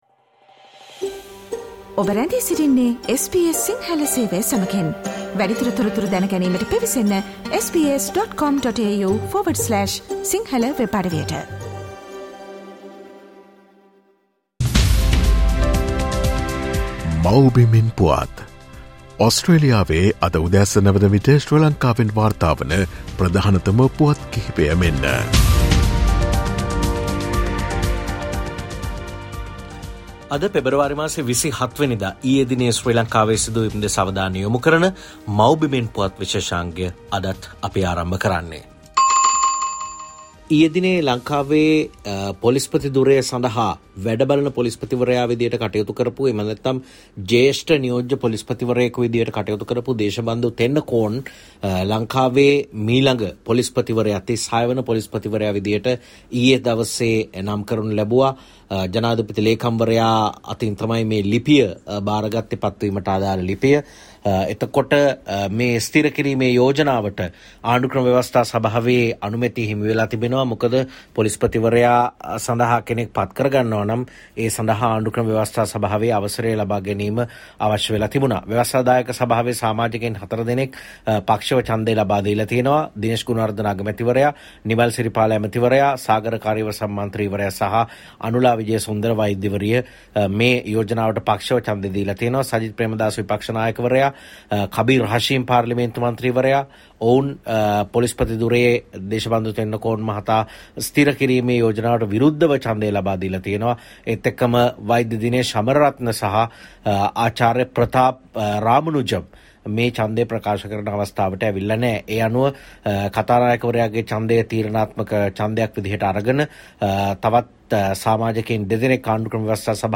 Here are the most prominent News Highlights of Sri Lanka.